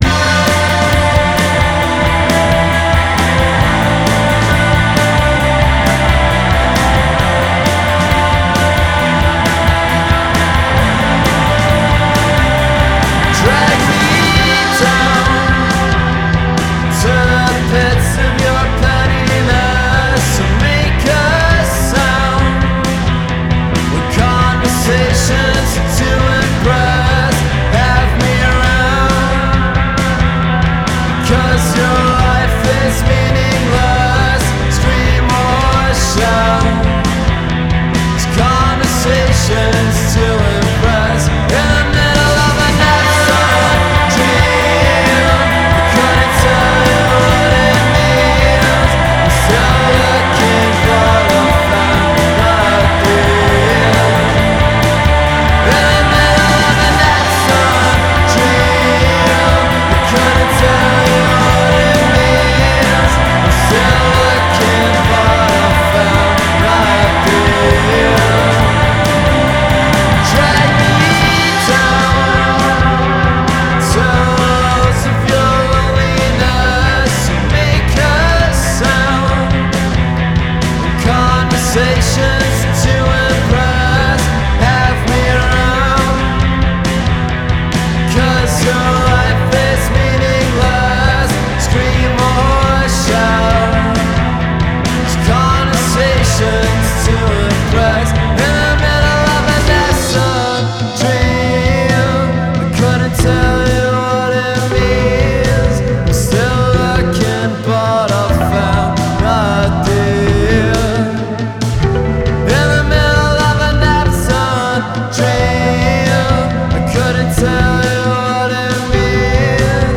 Irish three-piece
A track that is haunting and close to that of The Flaws.